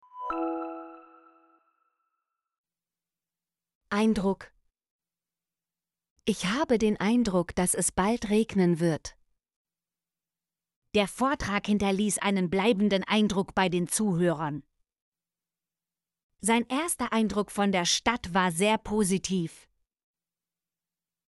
eindruck - Example Sentences & Pronunciation, German Frequency List